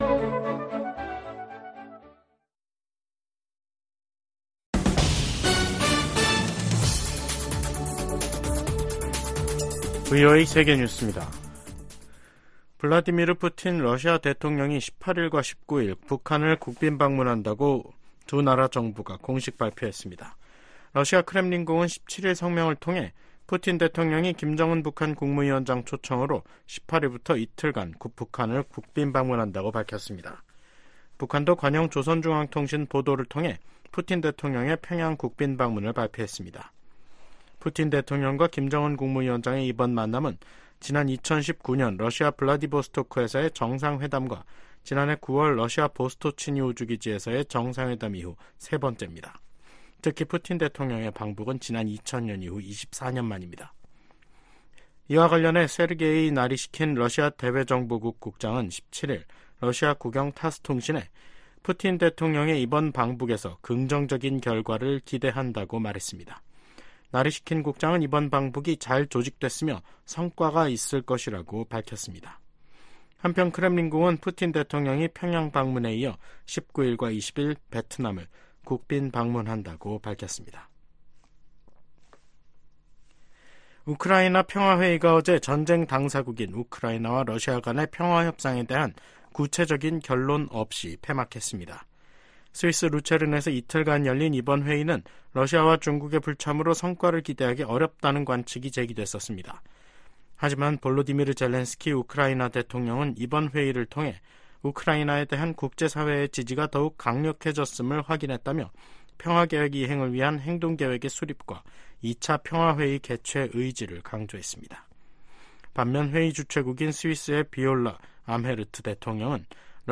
VOA 한국어 간판 뉴스 프로그램 '뉴스 투데이', 2024년 6월 17일 3부 방송입니다. 미국,영국,프랑스 등 주요 7개국, G7 정상들이 북한과 러시아간 군사협력 증가를 규탄했습니다. 미국 하원이 주한미군을 현 수준으로 유지해야 한다는 내용도 들어있는 새 회계연도 국방수권법안을 처리했습니다. 북한의 대러시아 무기 지원으로 우크라이나 국민의 고통이 장기화하고 있다고 유엔 주재 미국 차석대사가 비판했습니다.